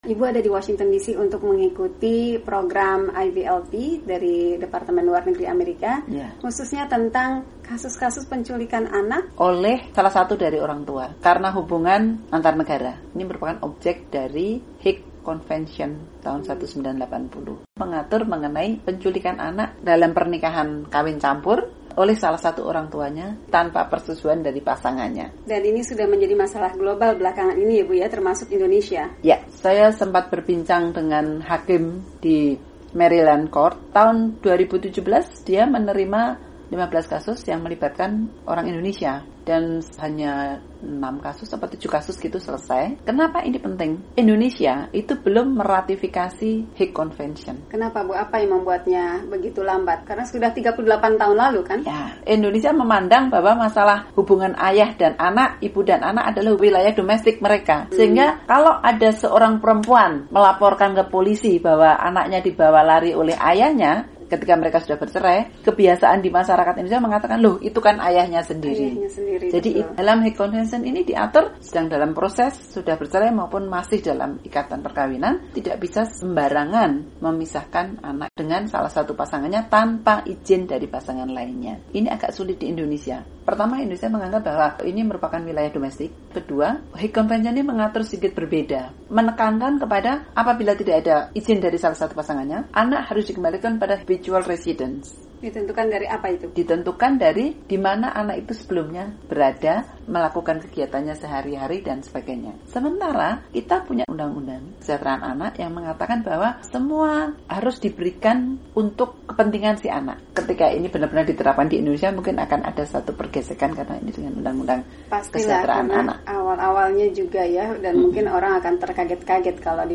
Indonesia akan meratifikasi Konvensi Den Hague mengenai penculikan anak oleh salah satu orangtuanya. Hakim Tinggi Dr. Ifa Sudewi menilai, situasinya sudah mendesak bagi Indonesia meratifikasi konvensi itu. Berikut ini kutipan perbincangan